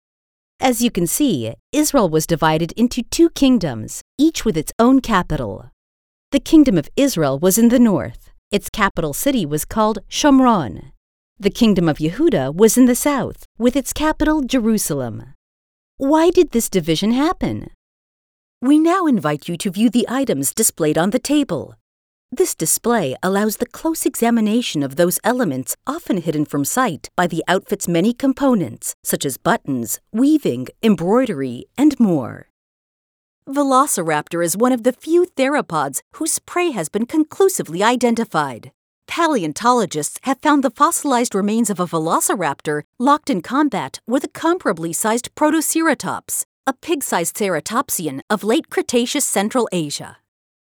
Cálido
Conversacional
Profesional